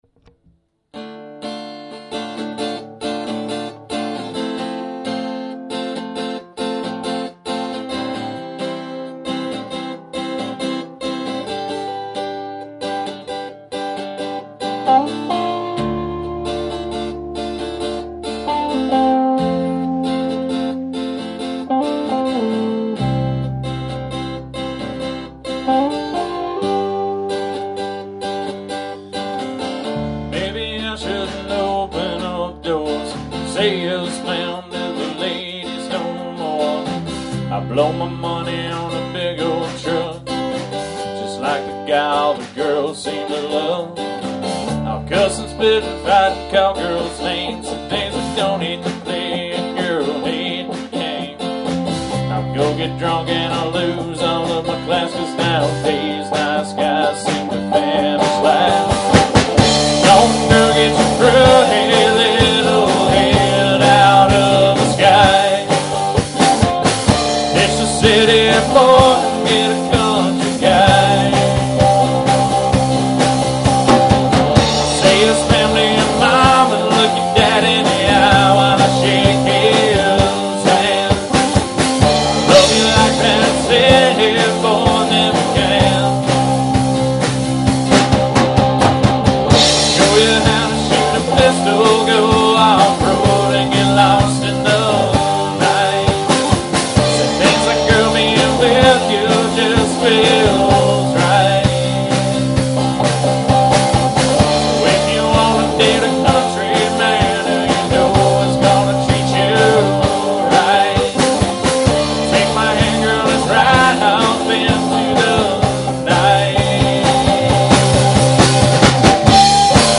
Lead Vocals/Guitar
Vocals/Guitars
Bass Guitar
Lead Guitar